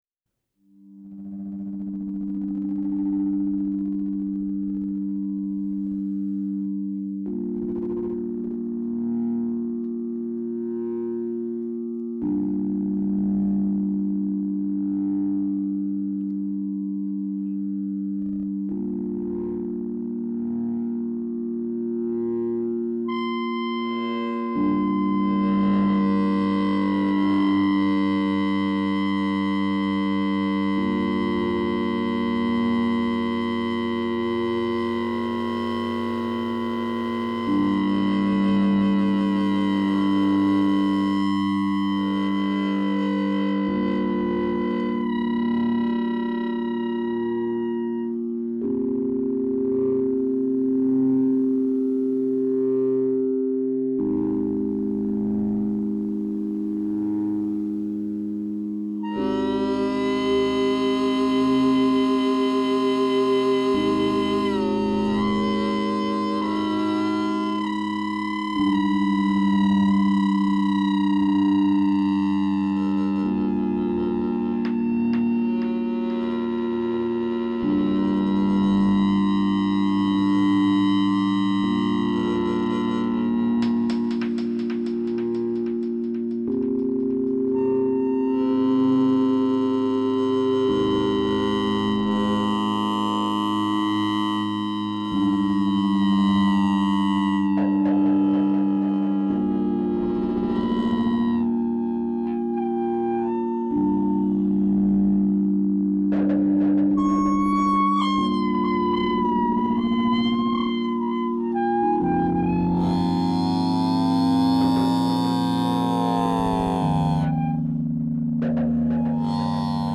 Posaune Trombone freie Improvisation Free Music